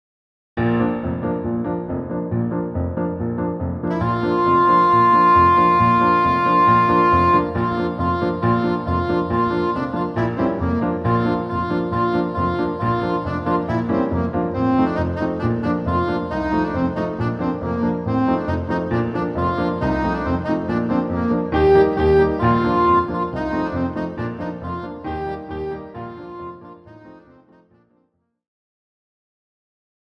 the clarinet version is entirely within the low register
Version 1    for Clarinet, Alto Saxophone or French Horn
Key: B flat major (and B flat minor)